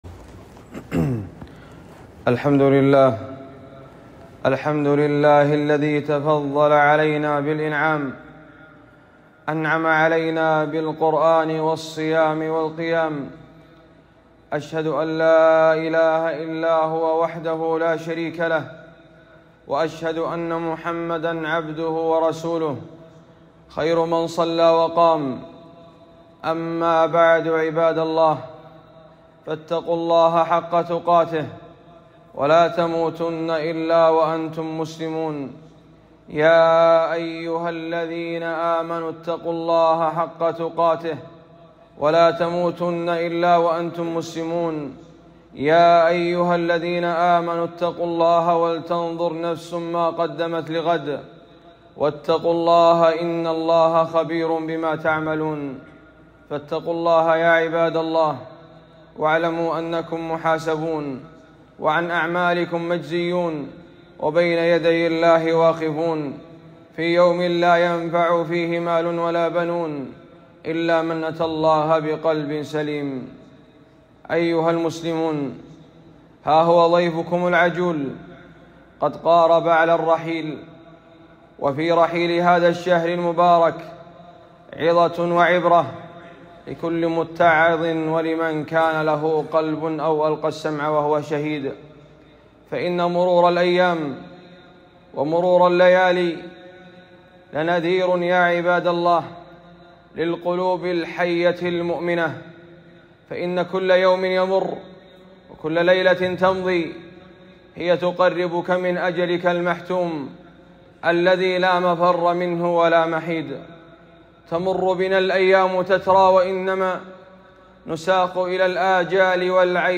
خطبة - عبادات ختام الشهر